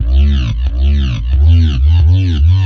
wobbly bass sounds » bass resample 4
描述：crazy bass sounds for music production
标签： resampling bass sounddesign wobble
声道立体声